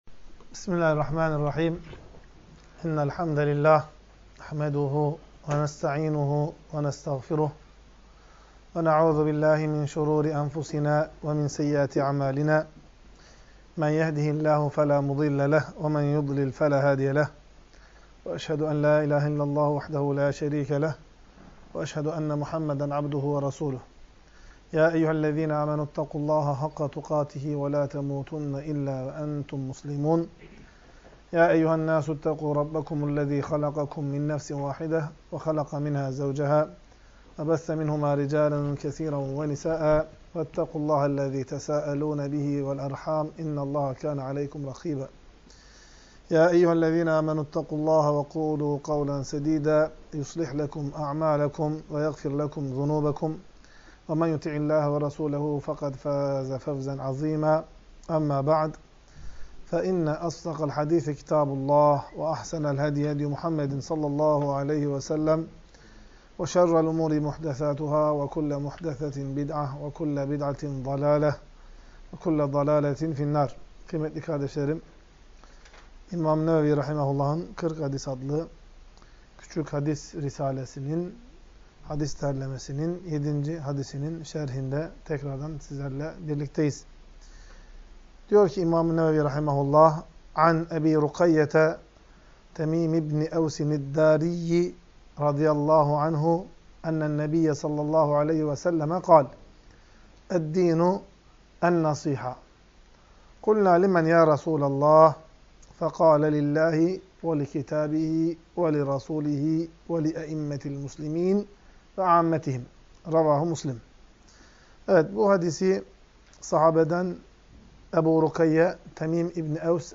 Ders - 7. Hadis